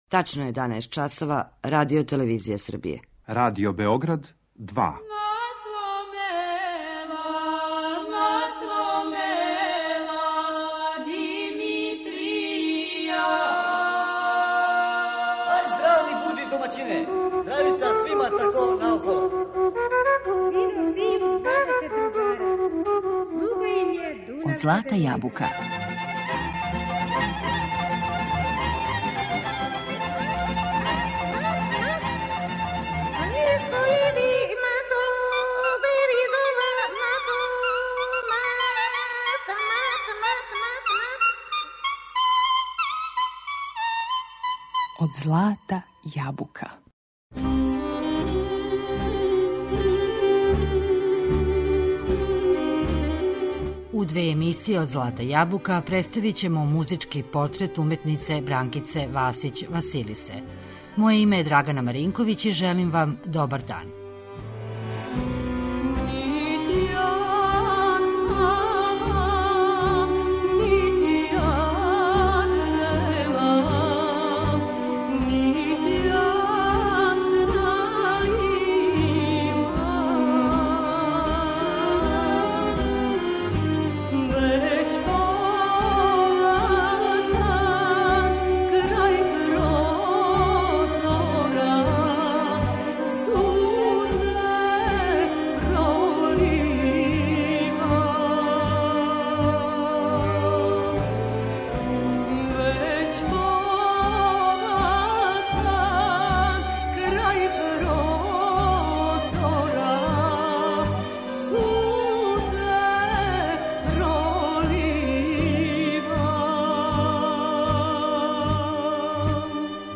Својим вокалом скренула је пажњу јавности изводећи старе, заборављене песме.